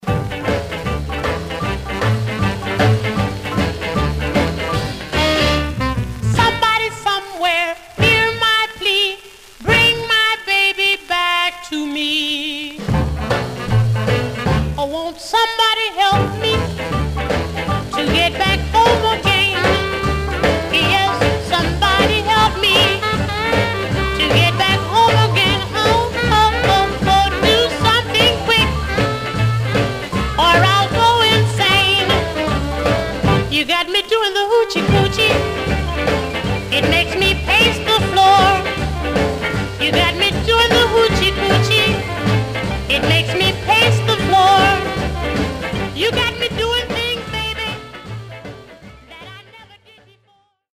Condition Surface noise/wear Stereo/mono Mono
Rythm and Blues